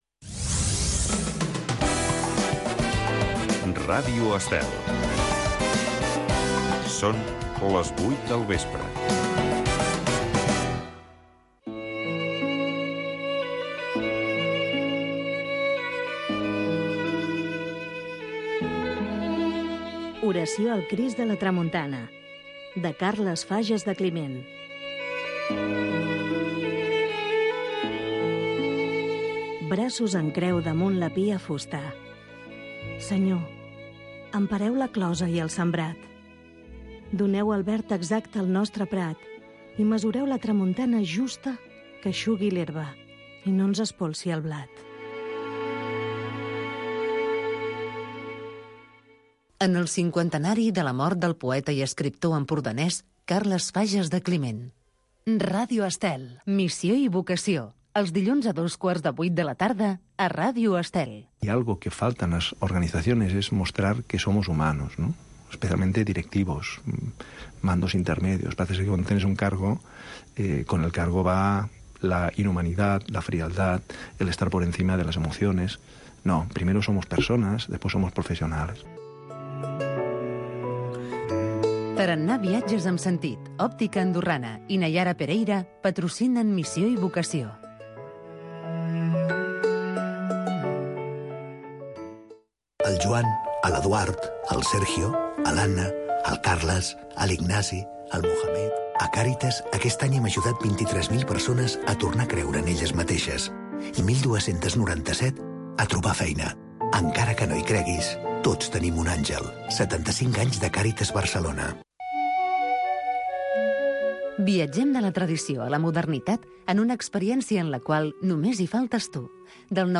La cantata del diumenge. Audició d’una Cantata de Johann Sebastian Bach, destinada al Diumenge corresponent del calendari luterà